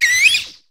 wattrel_ambient.ogg